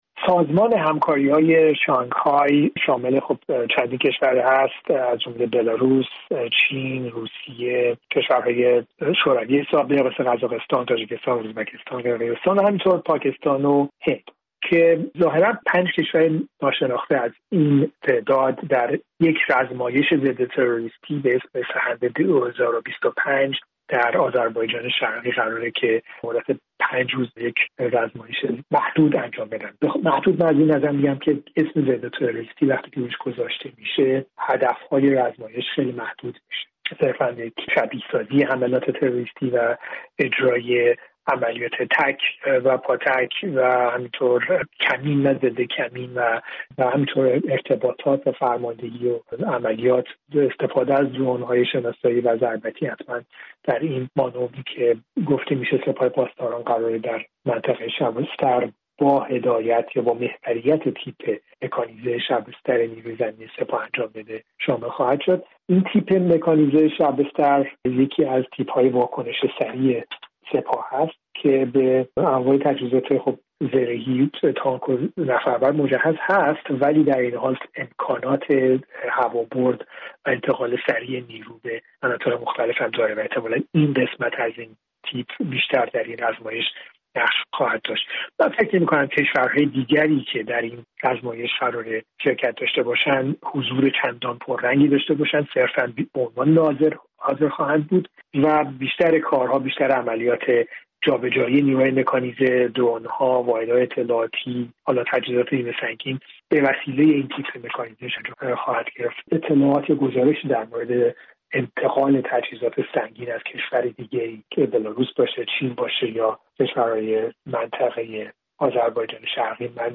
اهمیت مانور «سهند ۲۰۲۵» با حضور اعضای شانگهای در گفت‌وگو